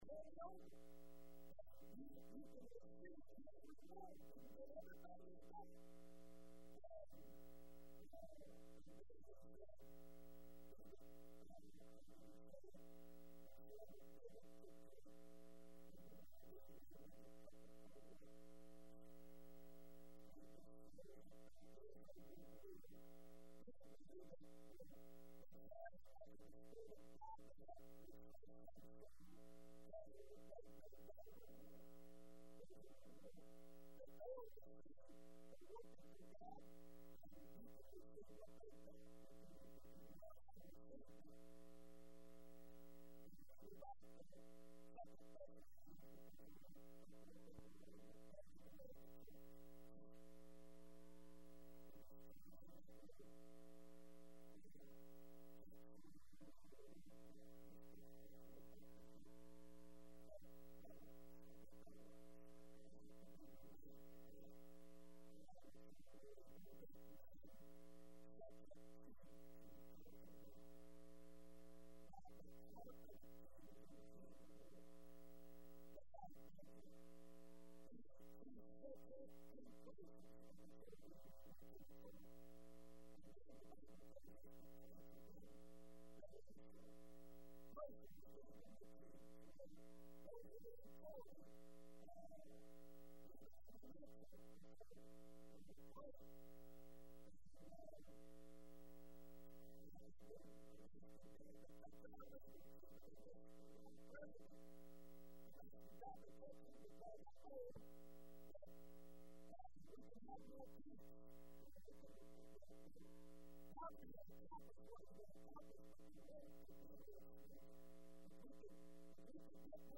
5/25/10 Wednesday Evening Service